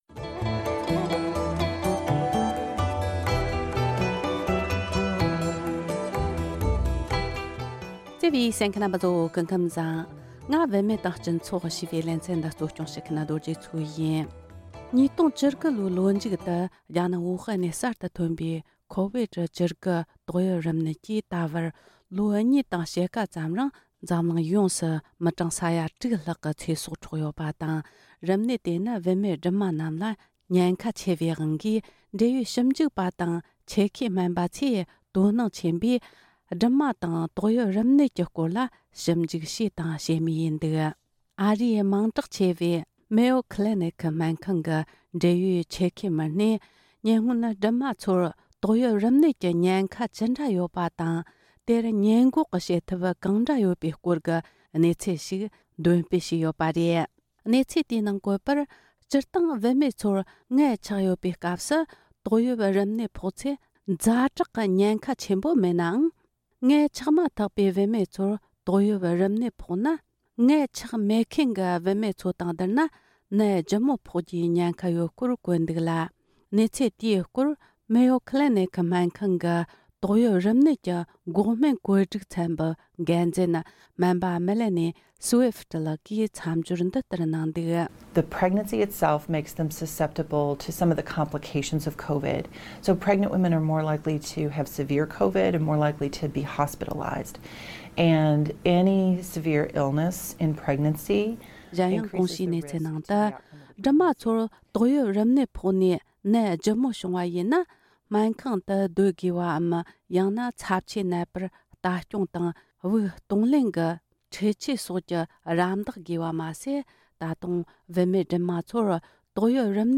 བུད་མེད་སྦྲུམ་མ་ཚོར་ཏོག་དབྱིབས་རིམས་ནད་བྱུང་ཚེ་ཉེན་ཁ་ཆེ་བ་ཡོད་སྟབས་འགོག་སྨན་བརྒྱབ་ན་ཁེ་ཕན་ཆེ་བ་ཡོད་པ། བུད་མེད་སྦྲུམ་མ་ཚོར་ཏོག་དབྱིབས་རིམས་ནད་བྱུང་ཚེ་ཉེན་ཁ་ཆེ་བ་ཡོད་སྟབས་འགོག་སྨན་བརྒྱབ་ན་ཁེ་ཕན་ཆེ་བ་ཡོད་པའི་སྐོར་སྨན་པས་ངོ་སྤྲོད་གནང་བ།
སྒྲ་ལྡན་གསར་འགྱུར། སྒྲ་ཕབ་ལེན།